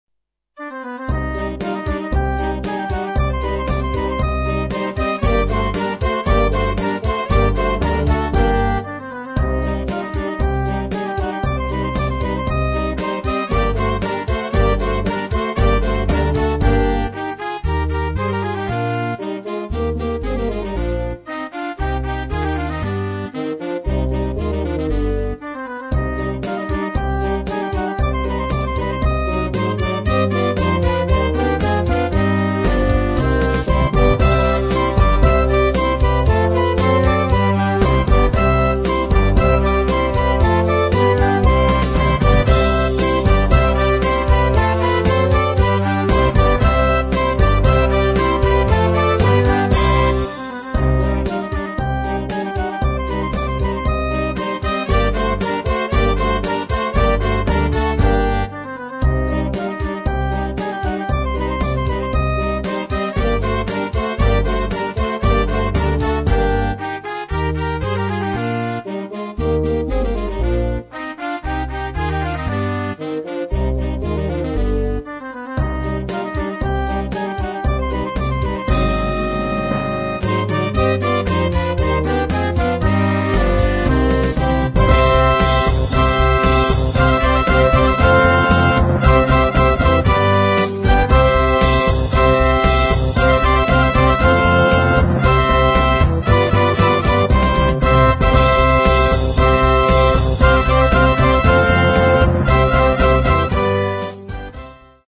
für Jugendblasorchester
Besetzung: Blasorchester